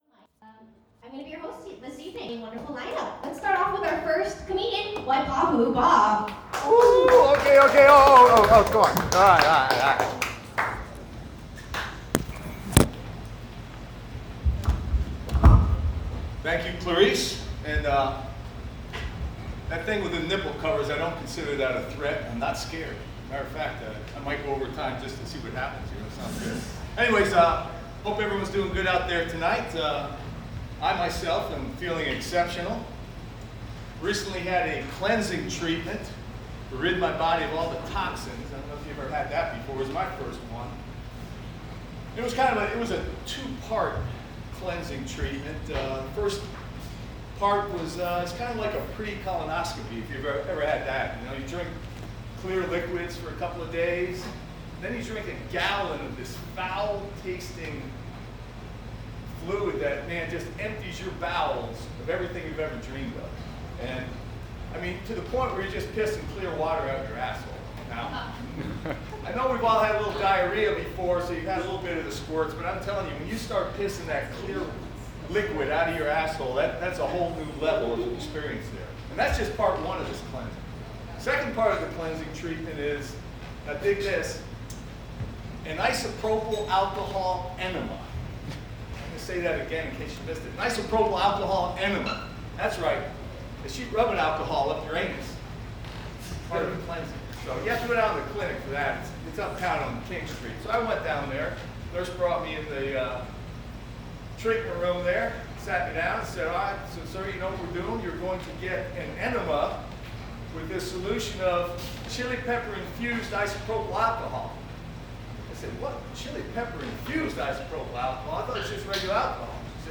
Stand-Up Comedy – Open Mic at the Downbeat Lounge – 22 Oct 2019